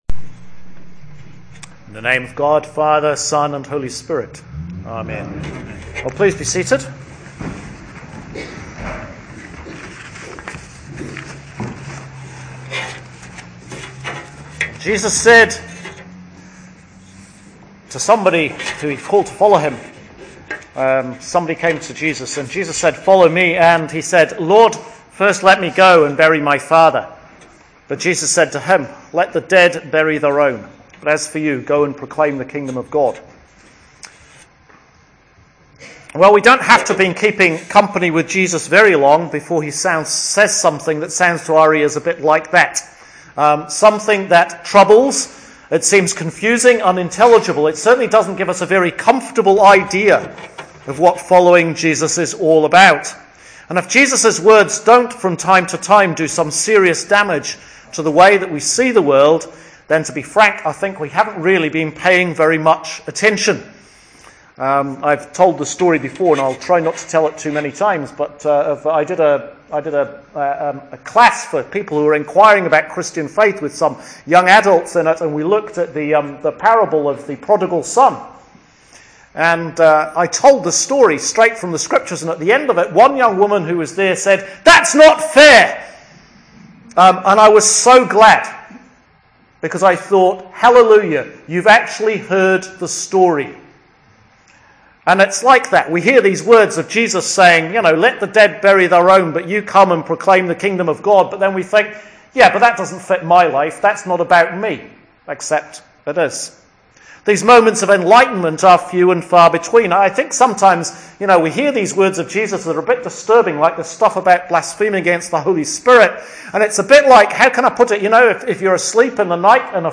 Sermon series on John’s Gospel